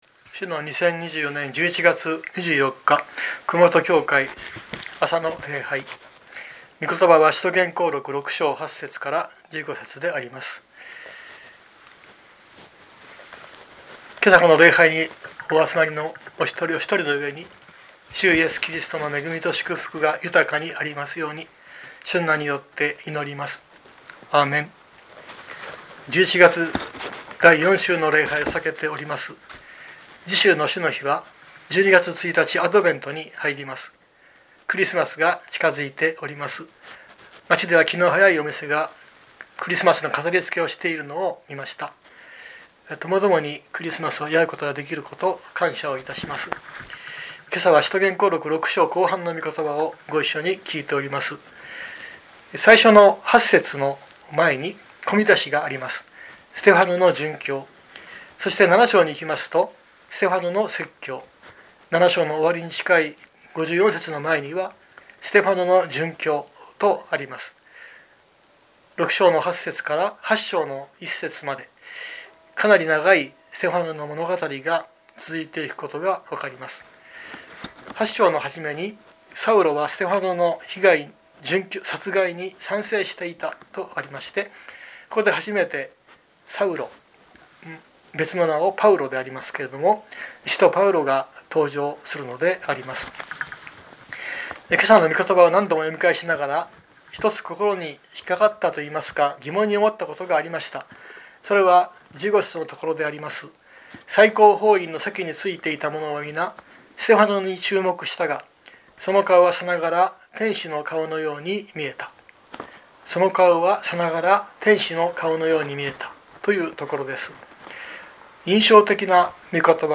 2024年11月24日朝の礼拝「さながら天使のように」熊本教会
熊本教会。説教アーカイブ。